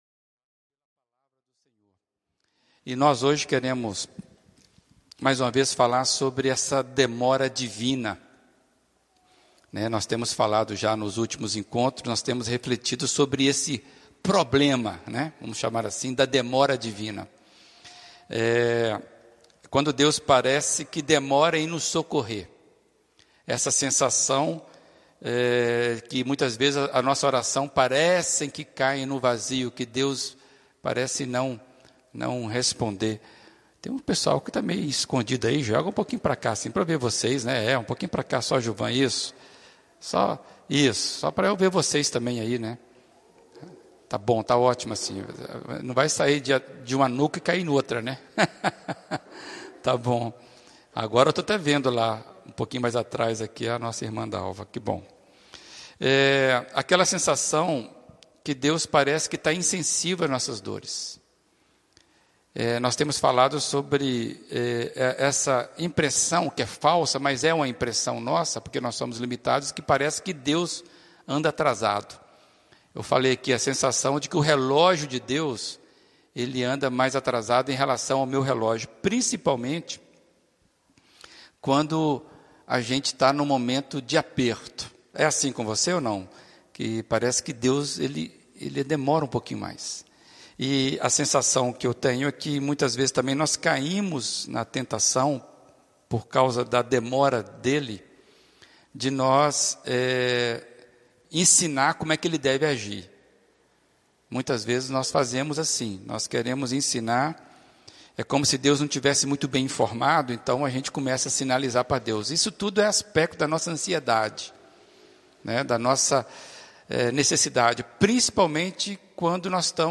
Primeira Igreja Batista de Brusque